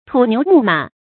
發音讀音
成語簡拼 tnmm 成語注音 ㄊㄨˇ ㄋㄧㄨˊ ㄇㄨˋ ㄇㄚˇ 成語拼音 tǔ niú mù mǎ 發音讀音 常用程度 常用成語 感情色彩 貶義成語 成語用法 聯合式；作賓語、定語；含貶義 成語結構 聯合式成語 產生年代 古代成語 近義詞 土雞瓦犬 成語例子 若門資之中而得愚瞽，是則 土牛木馬 ，形似而用非，不可以涉道也。